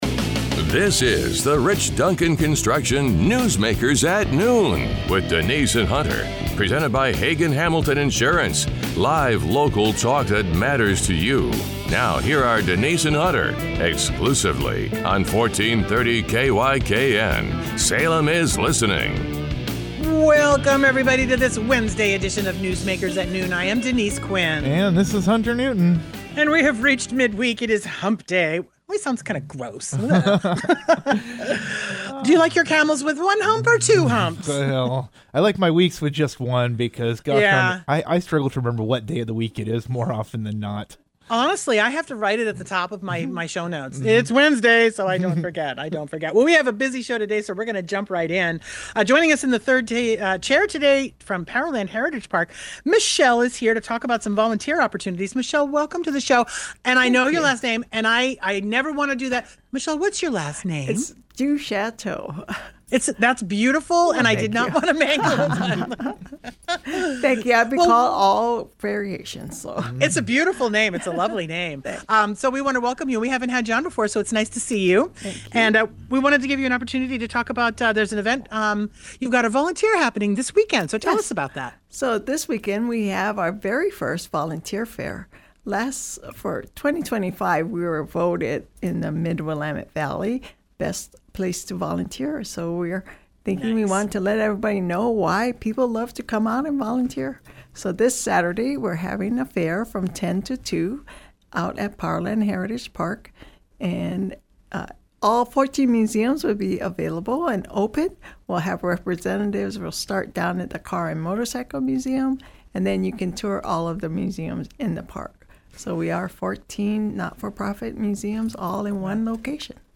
🏛 Guest: Salem Mayor Julie Hoy provided updates on city issues, including the status of the ethics complaint, as well as insights into the ongoing campaign season.